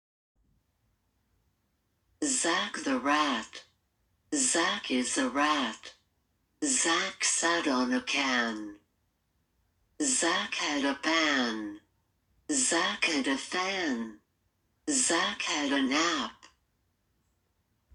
قوموا بالاستماع الى التسجيل الصوتي لمرفق في اسفل الصفحه والذي يحوي القراءة الصحيحه للقصه لكي تساعدكم.